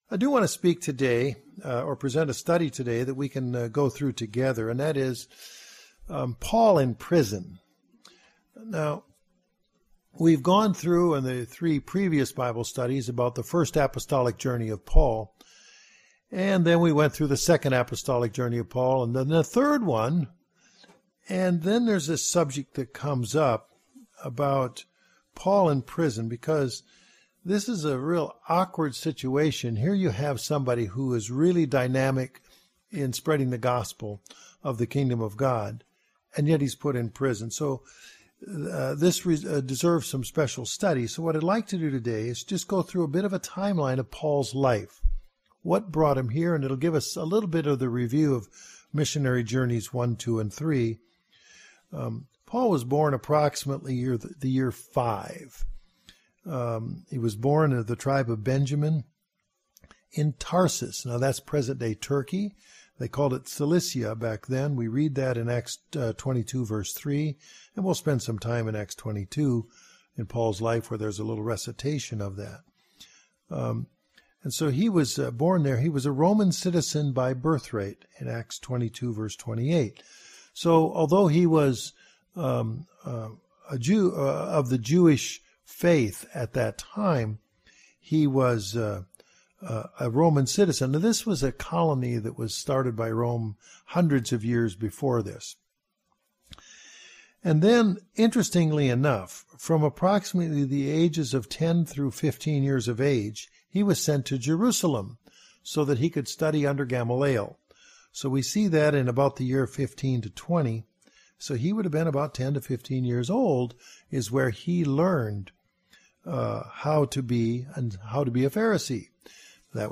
In this Bible study is reviewed Paul's journey toward imprisonment for the sake of the Gospel in the book of Acts.
Sermon